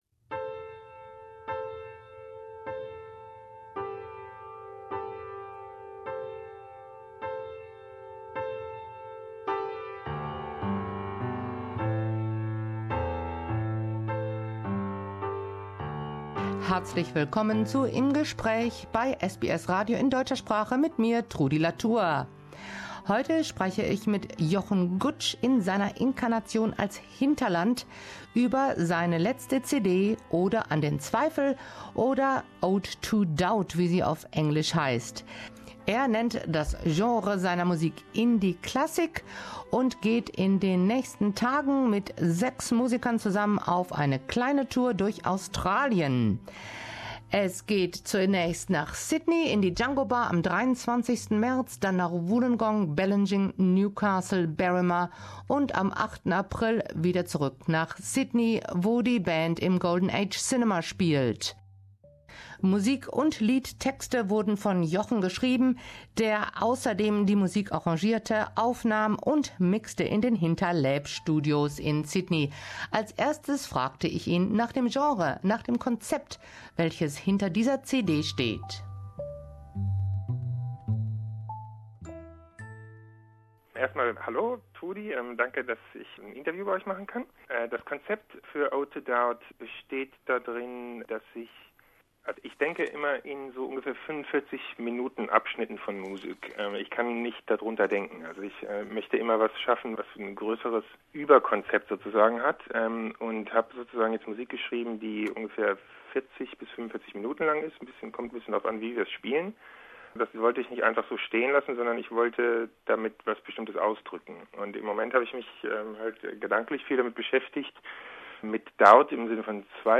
Im Gespräch: Die neue Hinterlandt CD – Ode an den Zweifel
Es ist konzeptuelle, wundersam ätherische Musik die einen in träumerische Gefilde versetzen kann.
Er hat uns auch die Genehmigung erteilt seine Musik in diesem Podcast zu spielen.